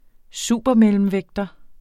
Udtale [ ˈsuˀbʌ- ]